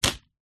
На этой странице собраны реалистичные звуки выстрелов из рогатки разными снарядами: от камней до металлических шариков.
Натянутую резинку рогатки отпустили